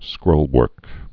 (skrōlwûrk)